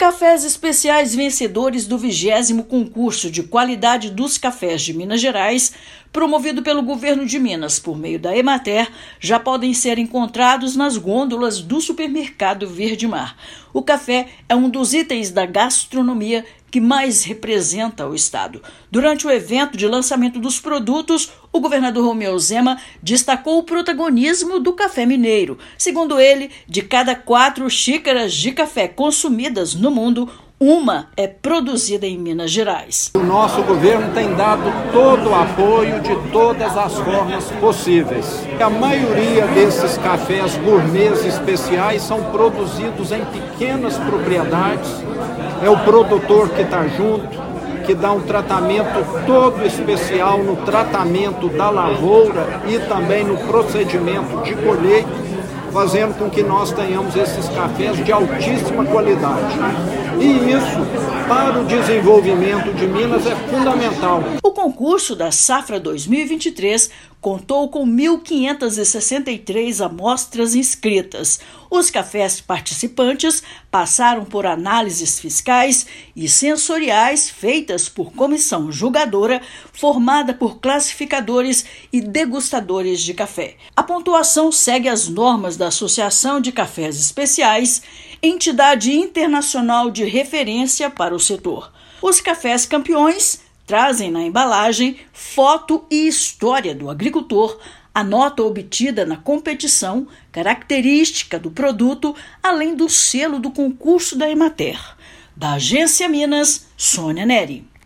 Durante lançamento de produtos vencedores, governador ressaltou apoio do Estado ao desenvolvimento da cafeicultura praticada em pequenas propriedades. Ouça matéria de rádio.